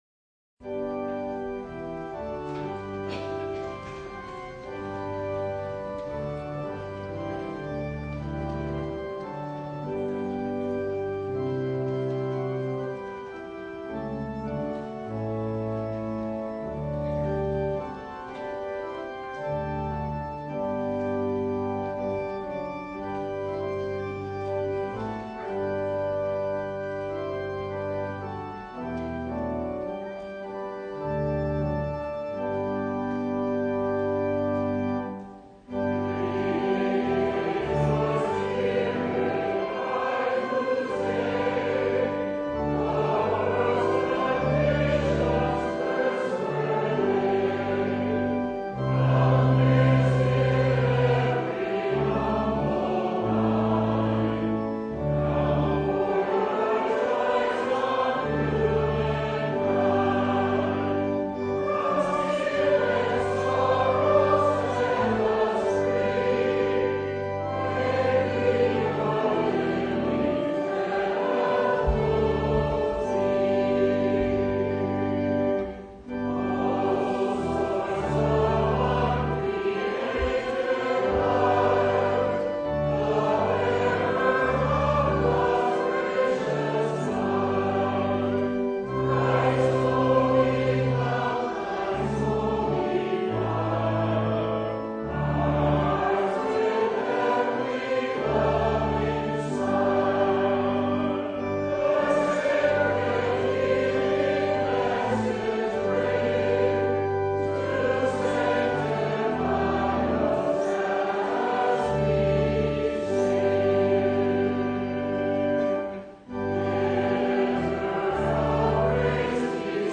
Passage: Luke 12:35-43 Service Type: Sunday
Full Service